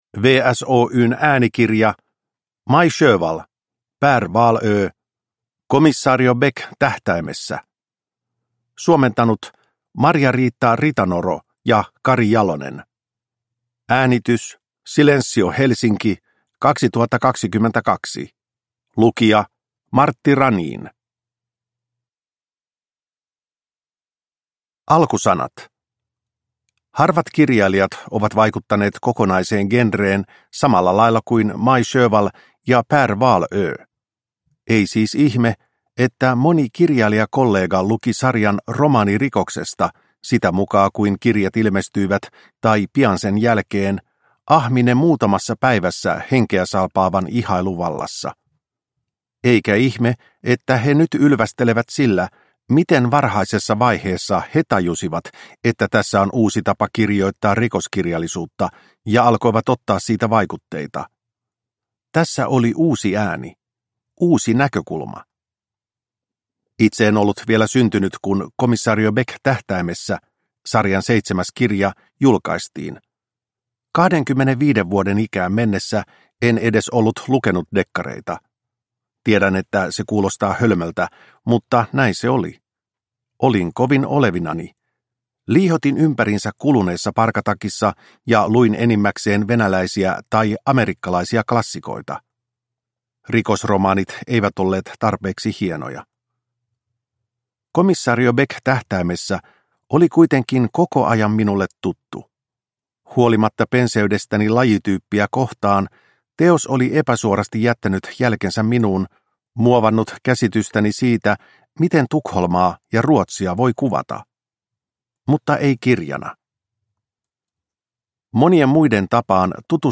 Komisario Beck tähtäimessä – Ljudbok – Laddas ner